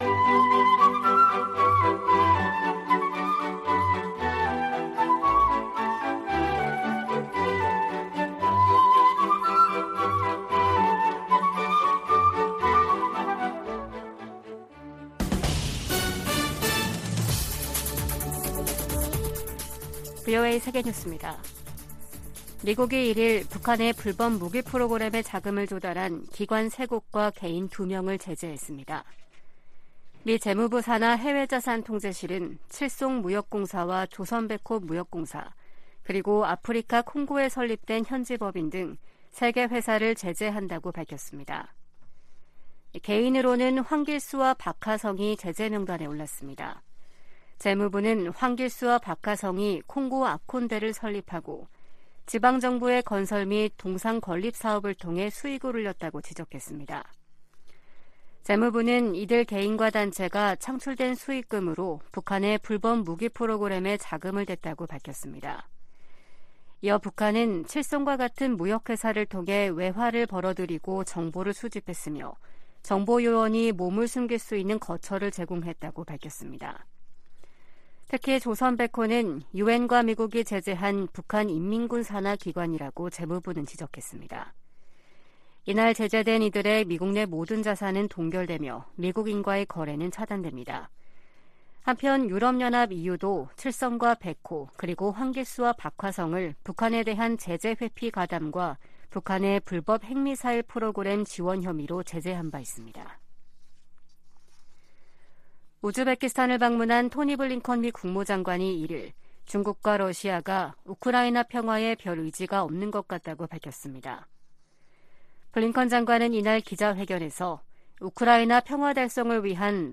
VOA 한국어 아침 뉴스 프로그램 '워싱턴 뉴스 광장' 2023년 3월 2일 방송입니다. 미국과 한국은 확장억제수단 운용연습(DSC TTX)을 성공적으로 진행했다며, 대북 확장억제 강화 필요성에 동의했다고 미 국방부가 밝혔습니다. 유엔 군축회의에서 한국 등이 북한의 핵과 탄도미사일 개발을 심각한 안보 도전이라고 비판했습니다. 윤석열 한국 대통령은 3.1절 기념사에서 일본은 협력 파트너로 변했다며 북 핵 대응 미한일 공조의 중요성을 강조했습니다.